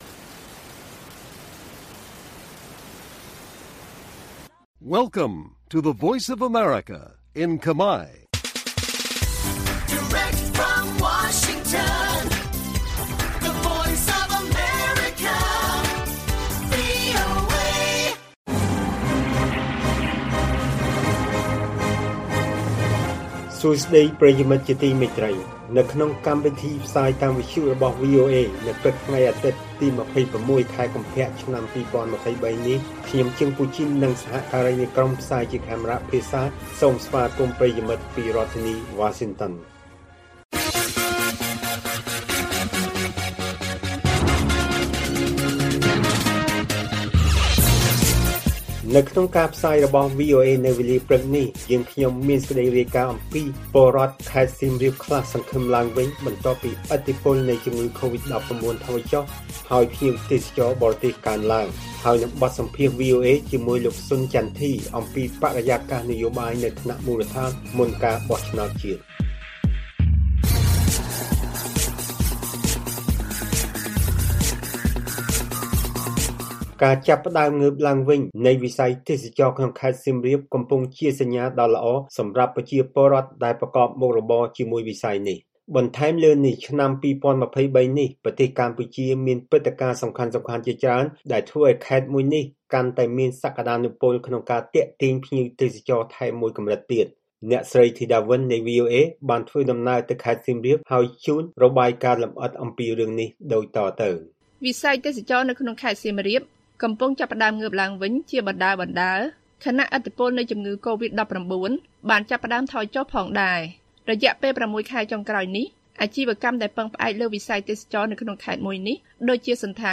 ព័ត៌មានពេលព្រឹក ២៦ កុម្ភៈ៖ ពលរដ្ឋខេត្តសៀមរាបខ្លះសង្ឃឹមឡើងវិញ បន្ទាប់ពីឥទ្ធិពលនៃជំងឺកូវីដថយចុះ ហើយភ្ញៀវទេសចរបរទេសកើនឡើង